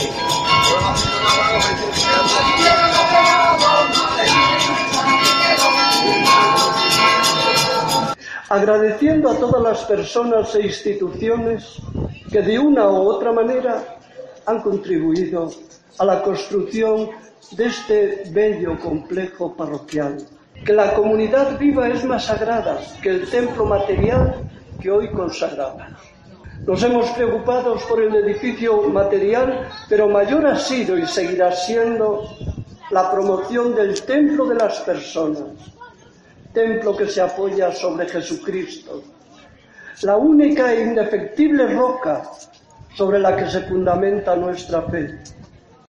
A las puertas del templo en el día del patrón de Milladoiro